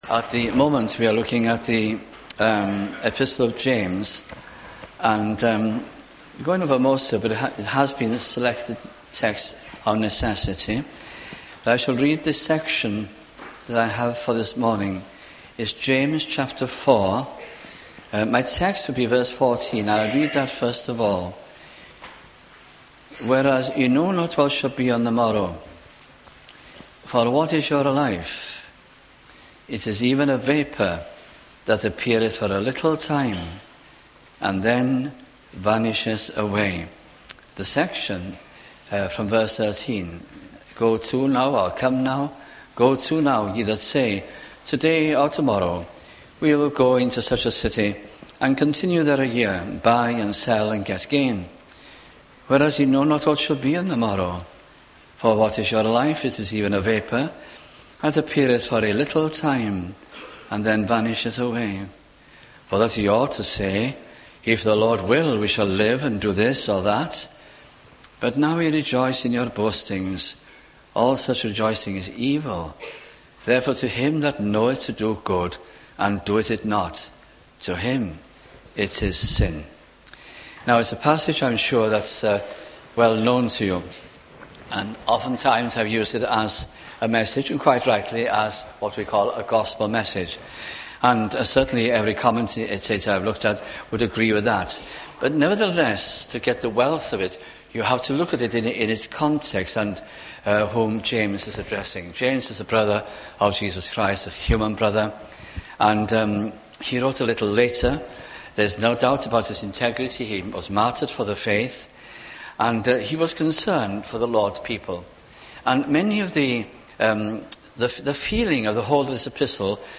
» James 2002 » sunday morning messages